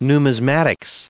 1884_numismatist.ogg